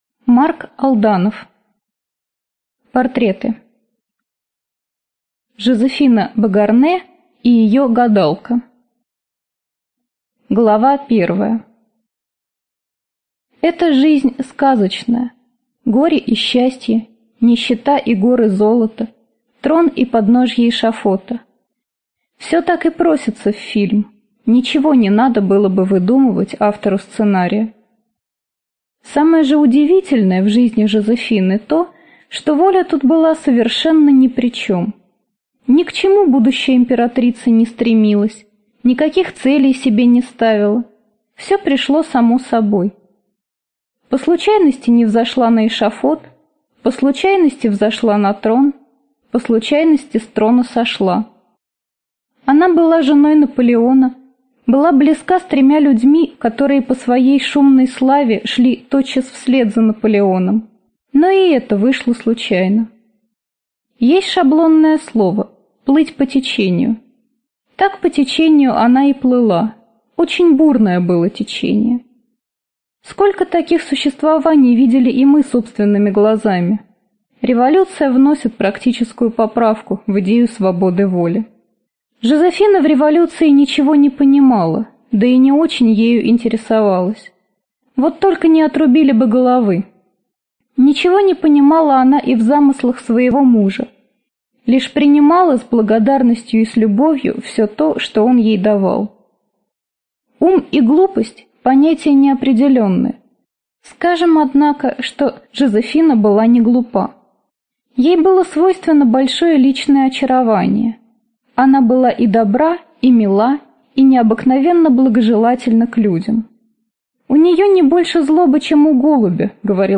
Аудиокнига Мистики.